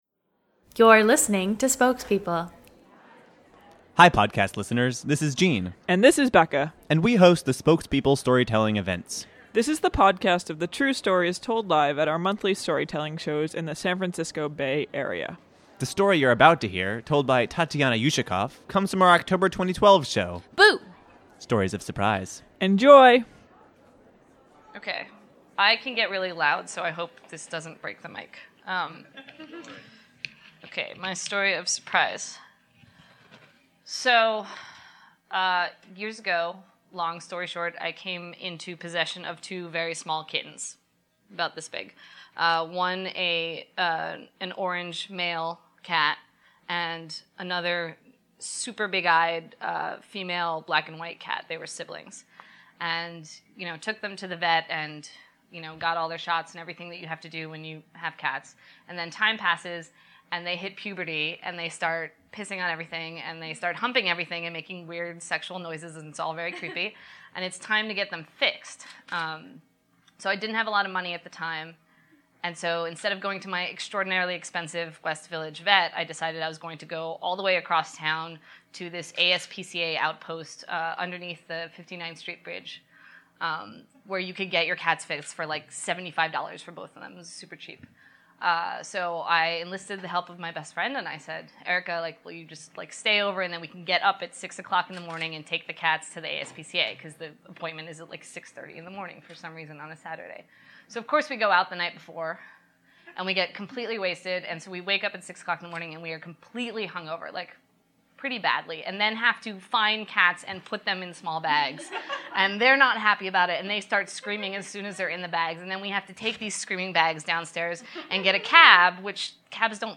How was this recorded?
Luckily this storyteller lived to tell the tale with which she regaled the Spokespeople audience at our October show, Boo! Stories of Surprise.